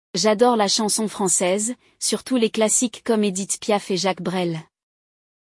No episódio de hoje do Walk ‘n’ Talk Level Up Francês, você vai escutar um diálogo entre dois amigos falando sobre música. Um deles acaba de comprar novos fones de ouvido e compartilha seu amor por esse gênero musical tão característico da França.
• Treinar sua escuta com uma conversa realista e natural;